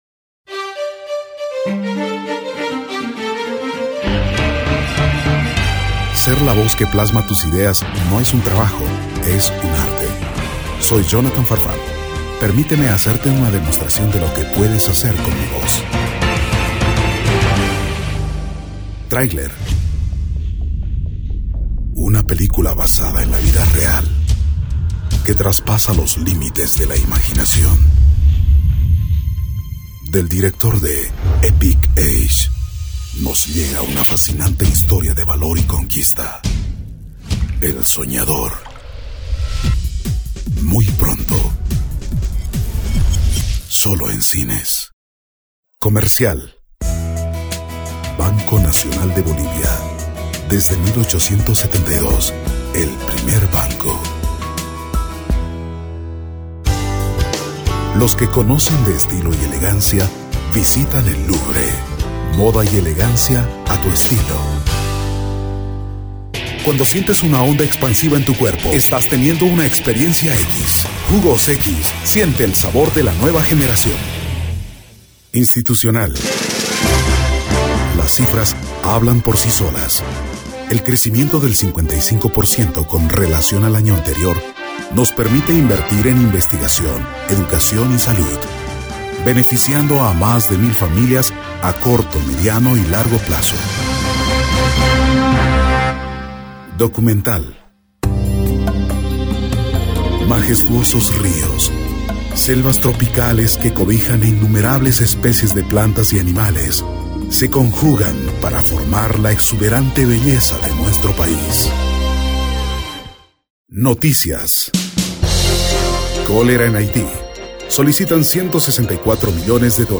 Voz profunda, tonos grave, medio, voz comercial, institucional,trailers
Sprechprobe: Werbung (Muttersprache):
Latin american and neutral spanish accent. Tv and radio commercial voiceover.